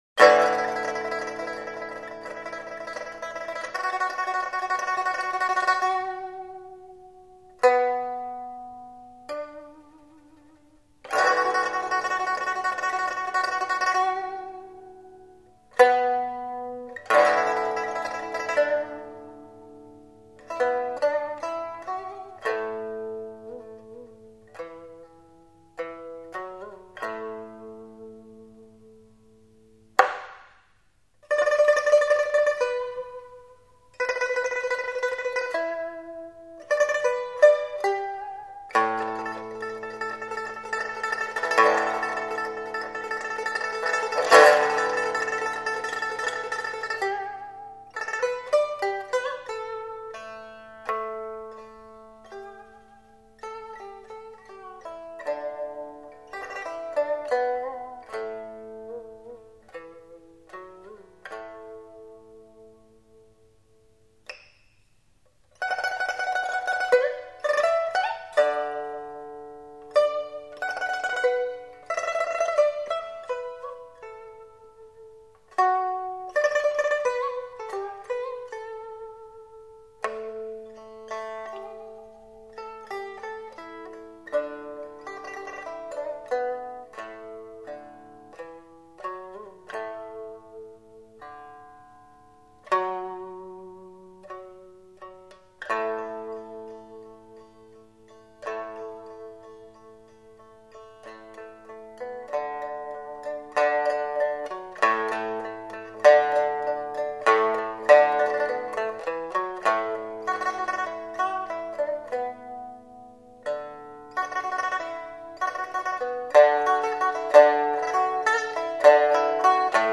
音乐类型: 民乐
唱片制式：ADD 数码处理历史性录音